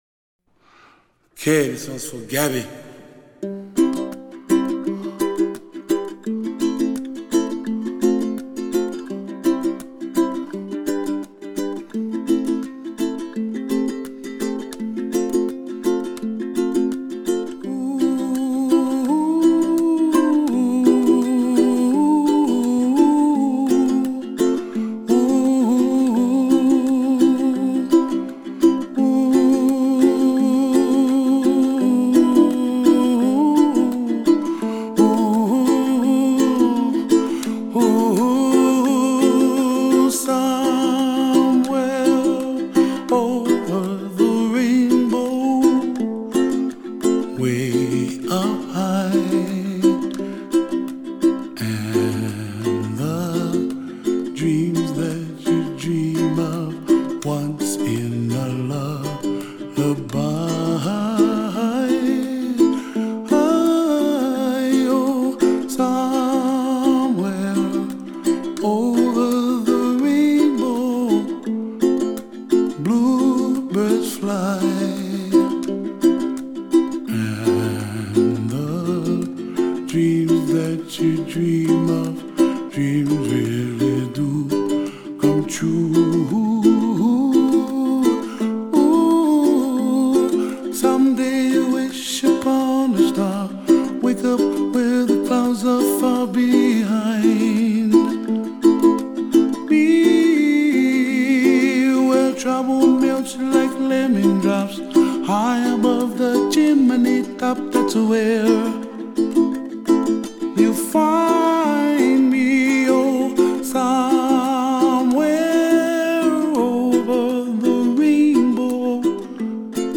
在纯美的音乐后面，正是他深深而脉脉涌动的温情。